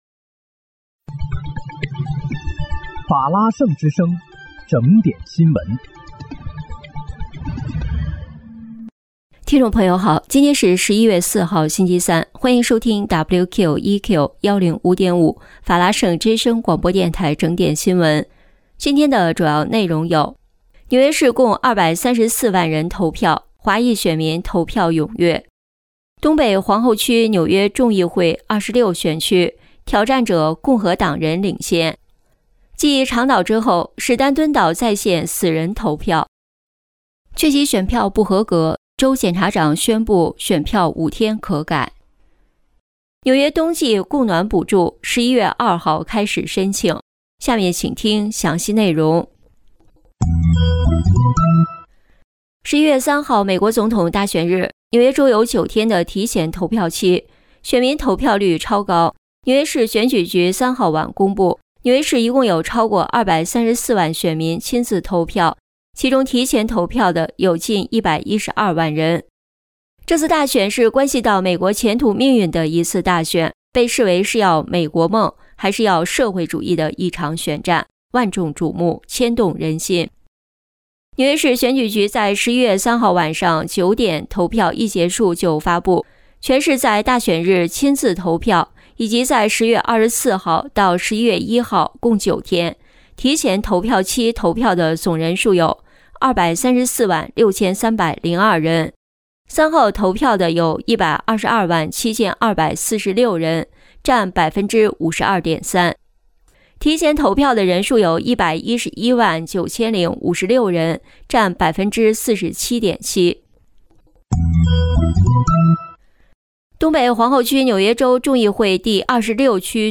11月4日（星期三）纽约整点新闻